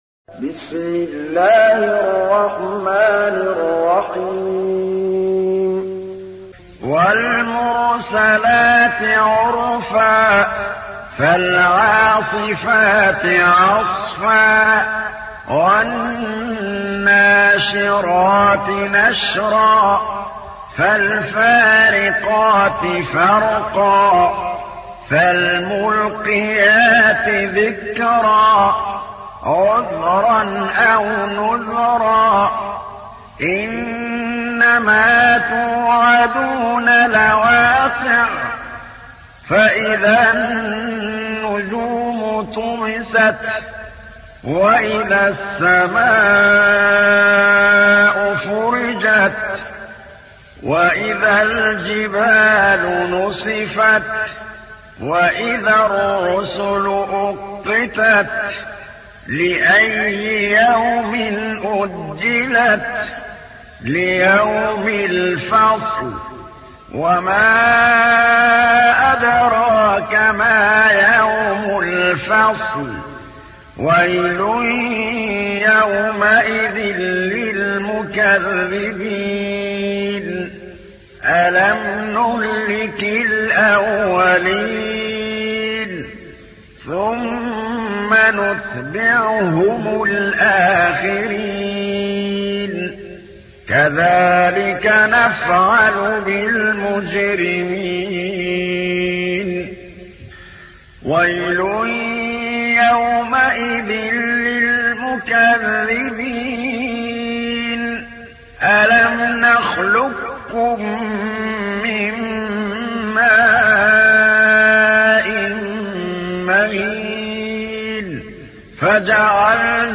تحميل : 77. سورة المرسلات / القارئ محمود الطبلاوي / القرآن الكريم / موقع يا حسين